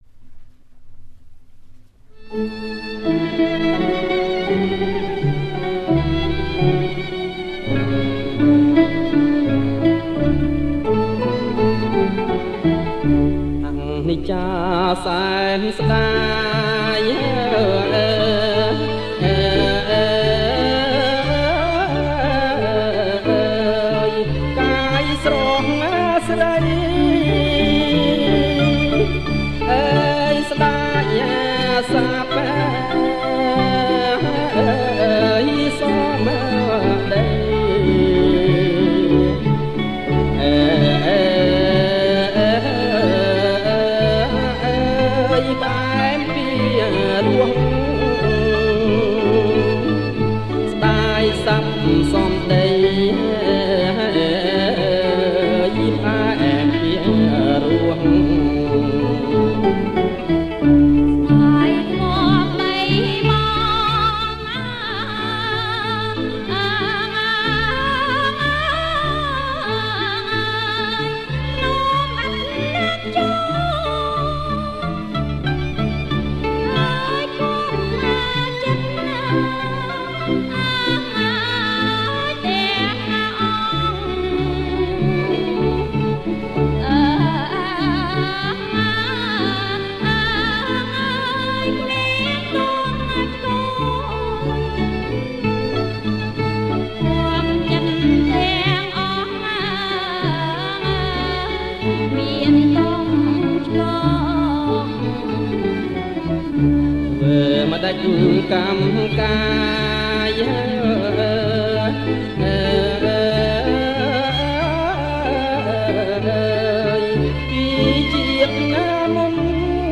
• ប្រគំជាចង្វាក់ Slow Rock